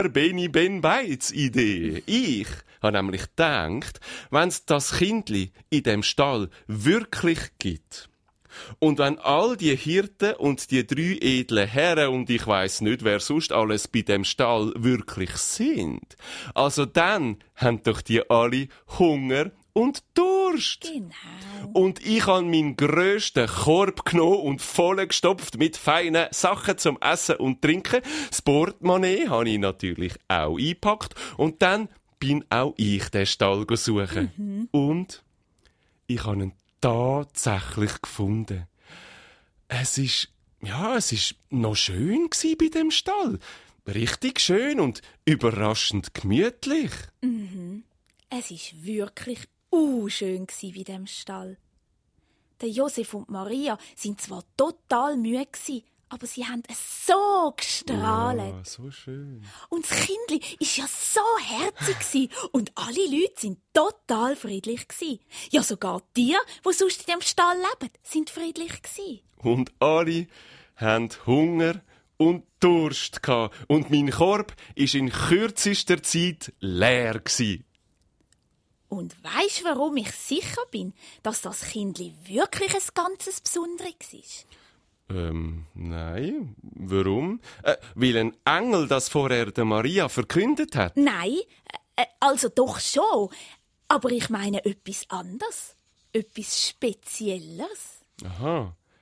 Ravensburger Beni Ben Baitz ✔ tiptoi® Hörbuch ab 4 Jahren ✔ Jetzt online herunterladen!